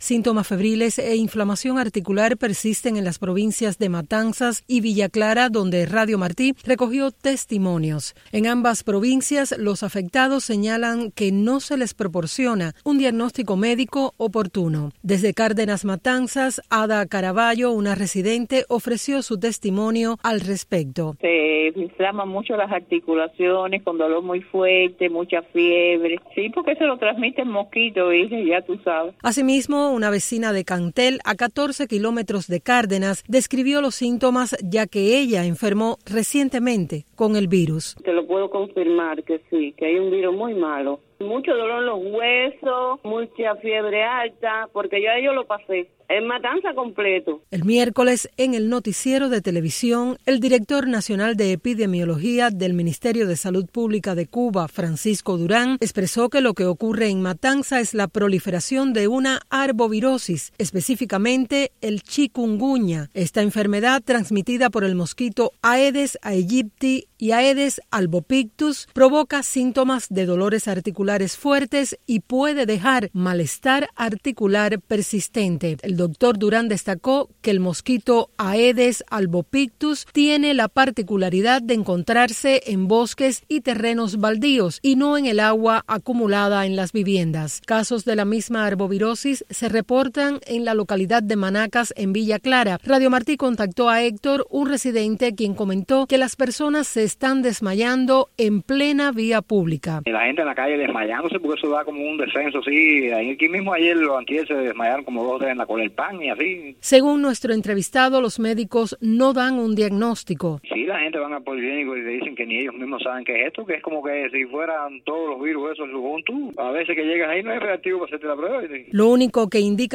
Las principales informaciones relacionadas con Cuba, América Latina, Estados Unidos y el resto del mundo de los noticieros de Radio Martí en la voz de nuestros reporteros y corresponsales